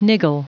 Prononciation du mot niggle en anglais (fichier audio)
niggle.wav